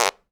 Comedy_Cartoon
cartoon_squirt_02.wav